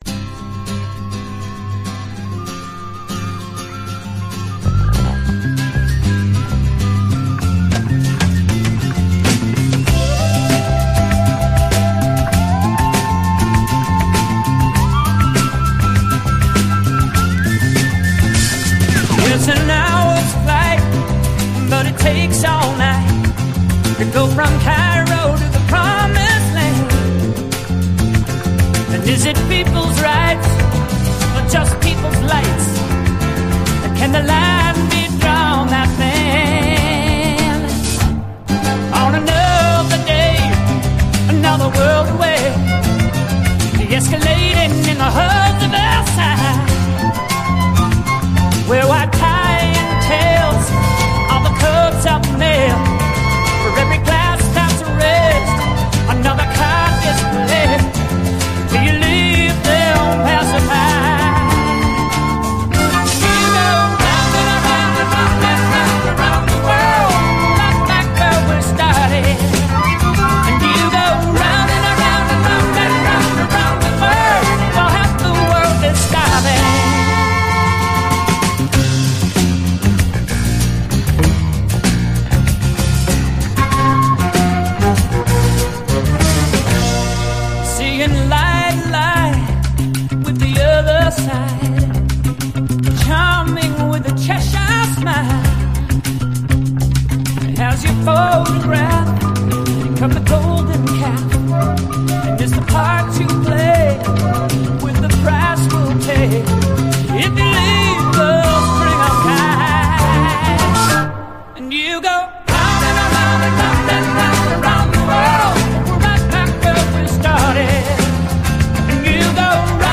SSW / AOR, ROCK
名S.S.W.デュオ！
青臭いまでに熱く胸を焦がす一曲です！